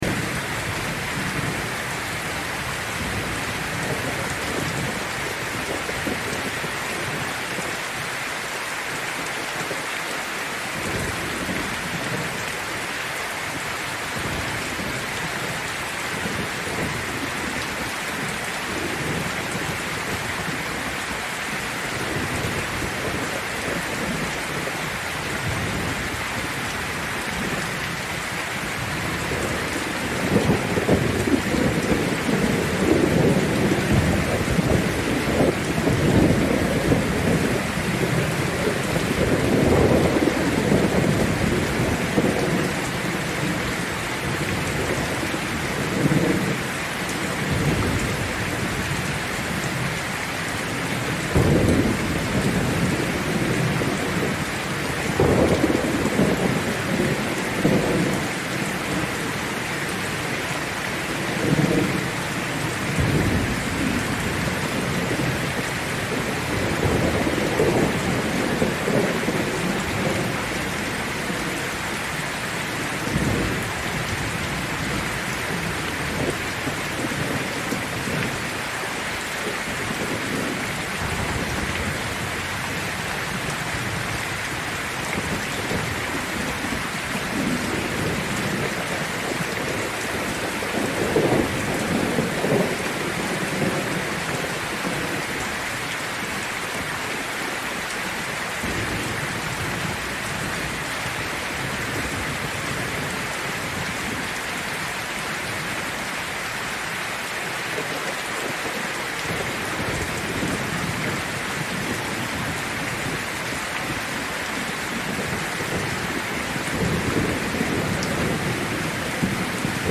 Name:Tropical Thunder Storm
Category:Nature sound from tropical thunder storm